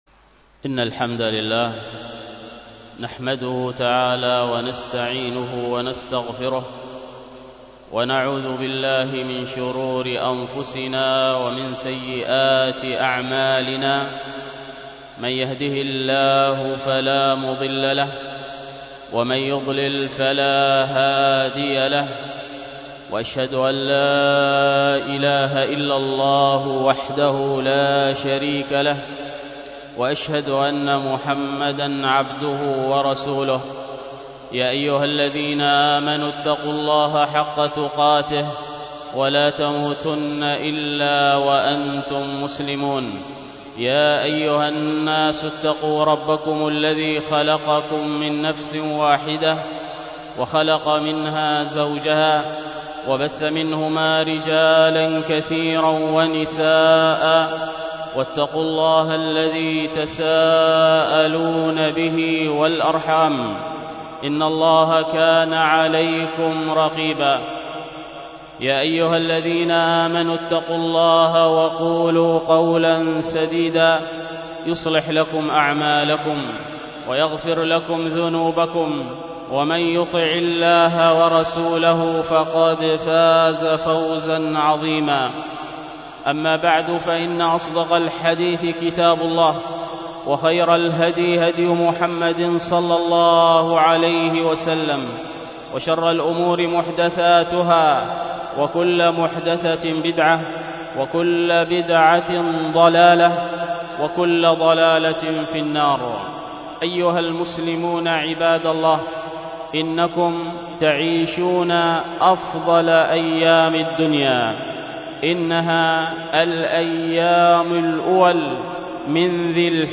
الخطبة
والتي كانت بمسجد التقوى بدار الحديث بالشحر